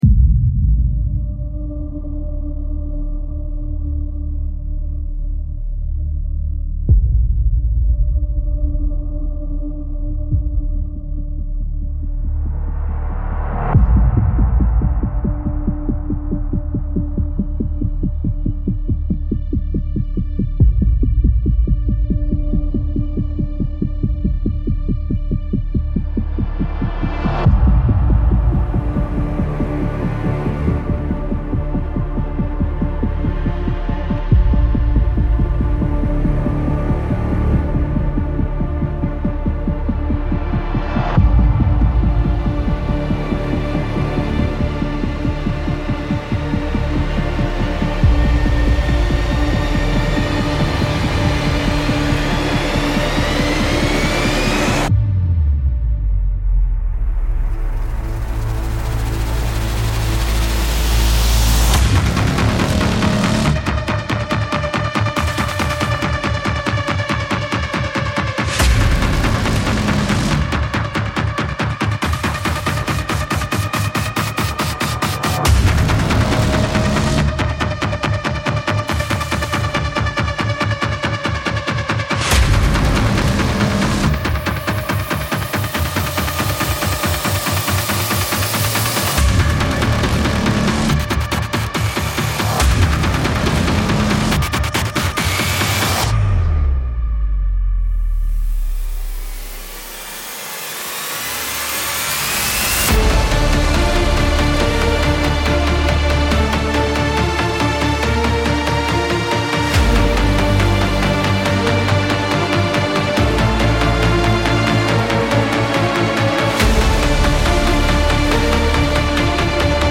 Dissonant brass stabs and distorted low strings clash.